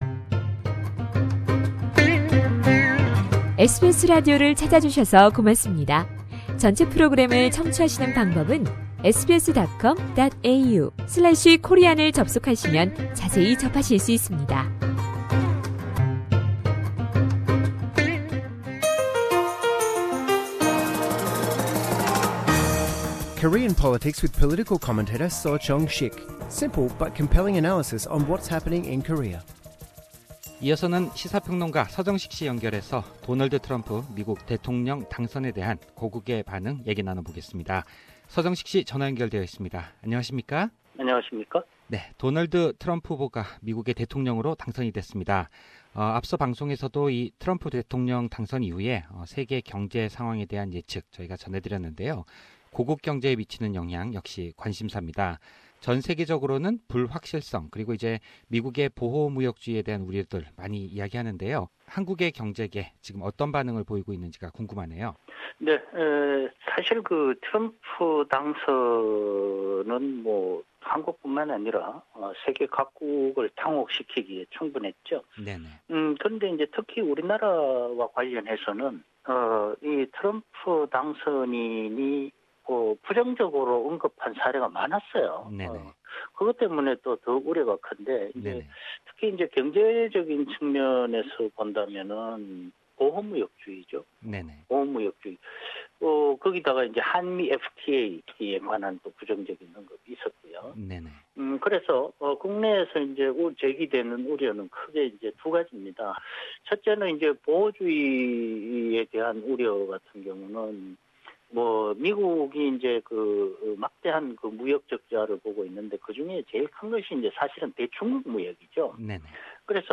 Discuss with Korean political commentator